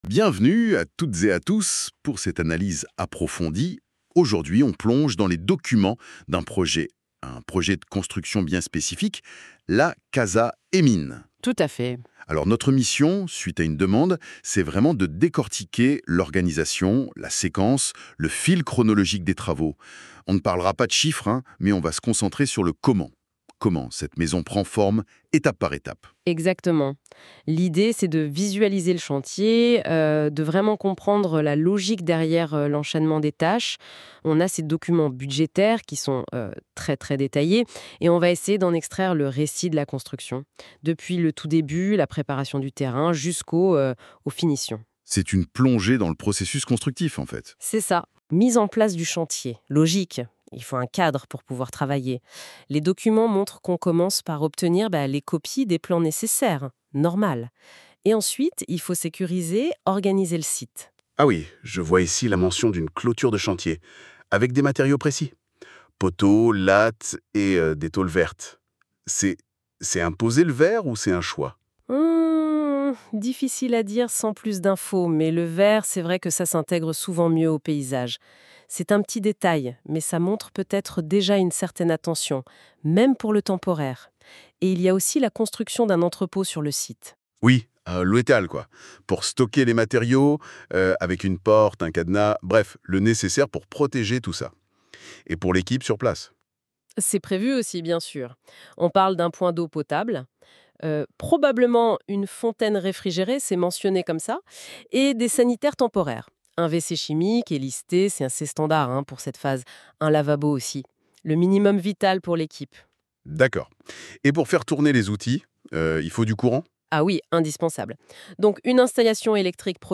Vous trouverez ci-dessous une discussion générée par intelligence artificielle (NotebookLM), qui vous décrit d’une façon à la fois globale, chronologique et assez détaillée, les différentes phases de construction de la maison. Cette analyse est basée sur un seul fichier, le fichier Excel détaillé du budget de la maison.